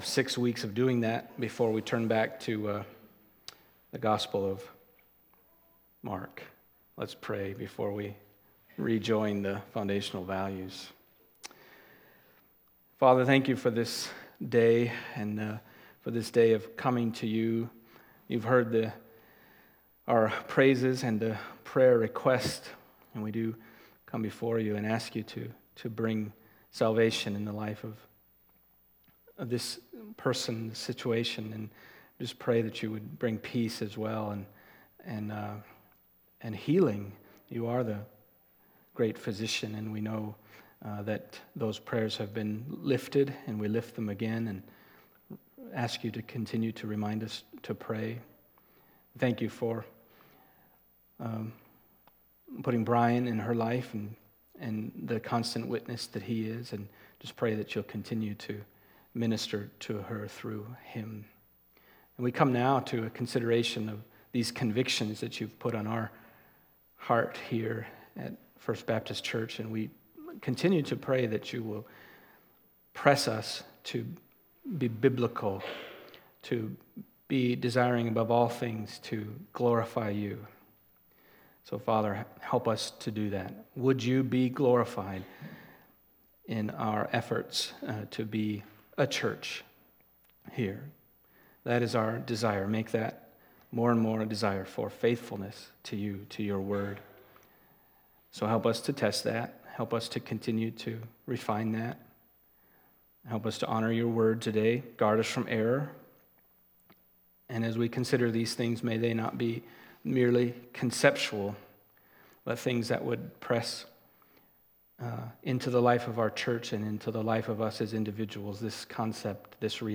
Foundational Values Service Type: Sunday Morning 10.